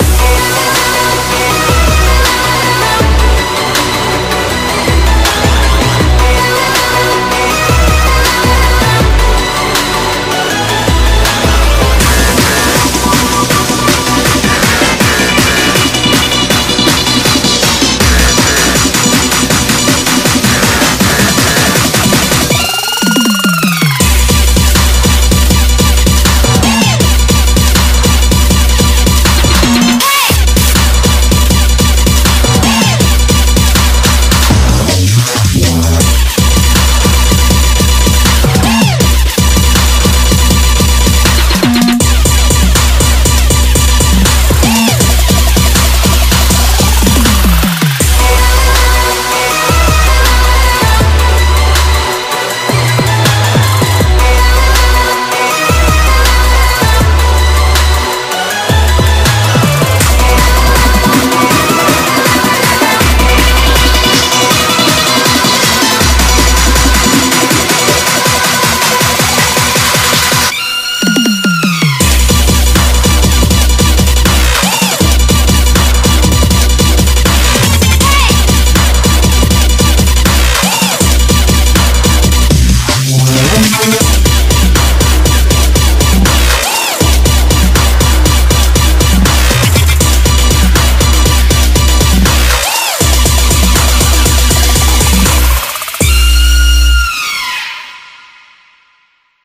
BPM160
Audio QualityCut From Video